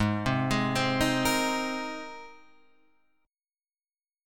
G#add9 chord {4 3 6 5 4 6} chord